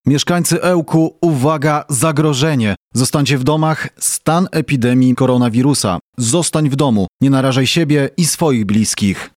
Takie komunikaty z radiowozów straży miejskiej usłyszycie na ulicach Ełku.